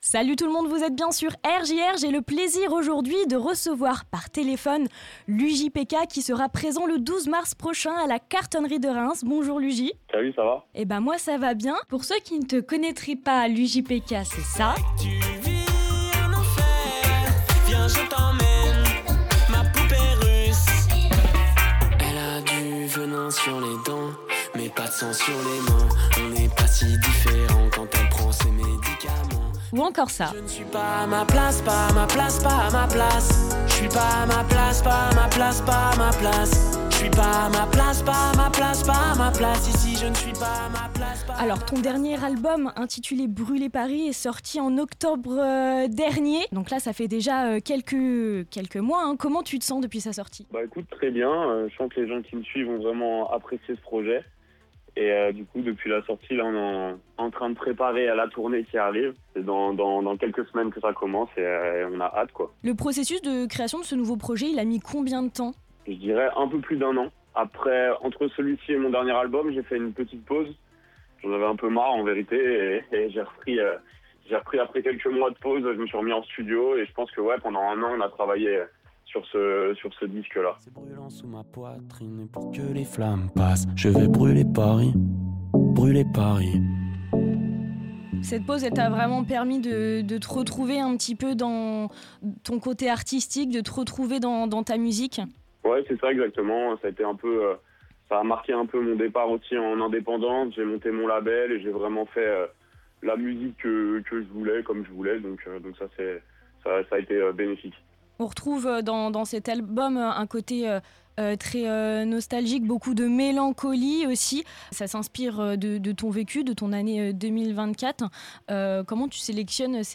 interview-lujipeka-pad-podcast-01.mp3